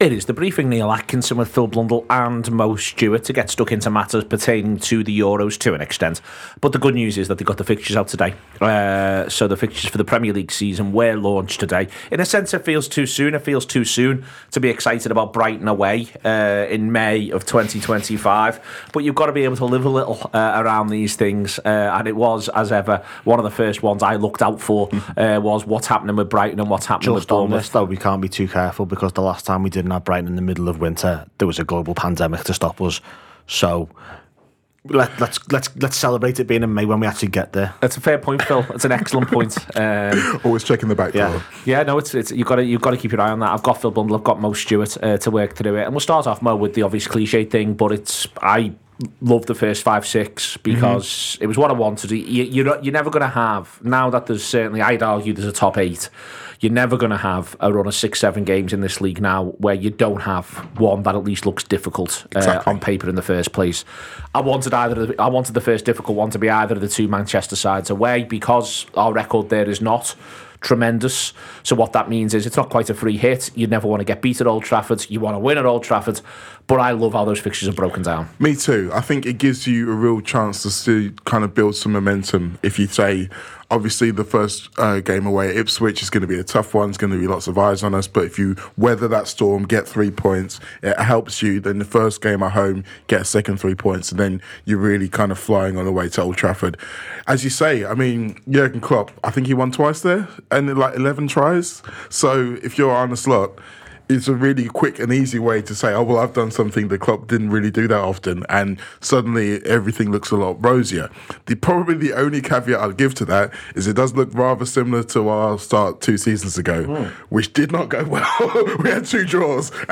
The Briefing is The Anfield Wrap’s daily discussion around all the latest Liverpool FC news this summer, including The Reds’ 2024-2025 Premier League fixtures.